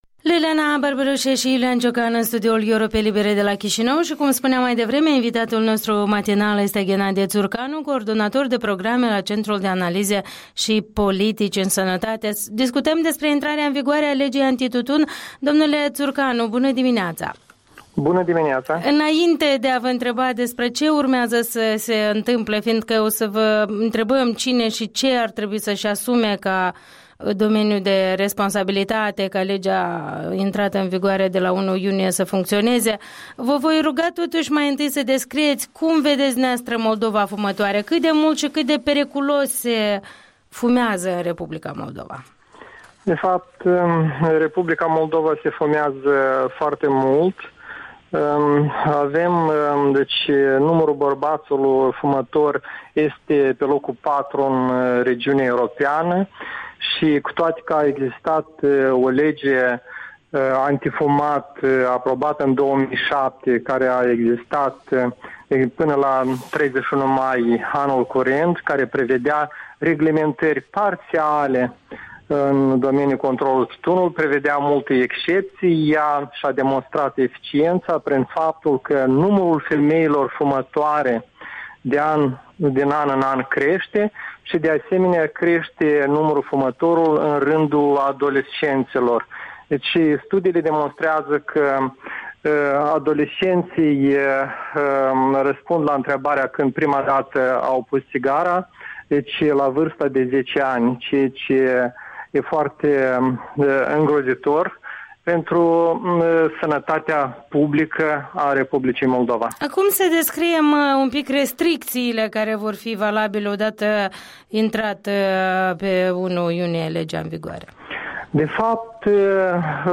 Interviul dimineții cu un coordonator de programe la Centrul de analize și politici în sănătate.